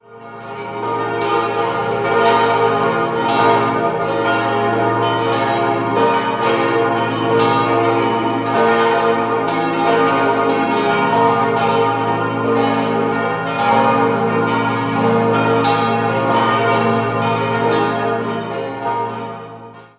Jahrhundert neu errichtet: Das Schiff von 1778 bis 1780, Chor und Turm 1784 bis 1786. 6-stimmiges Geläute: b°-des'-f'-as'-b'-des'' Alle Glocken wurden von der Firma Rüetschi in Aarau gegossen: Die Glocken 2, 3, 4, und 6 im Jahr 1860, die beiden anderen im Jahr 1959.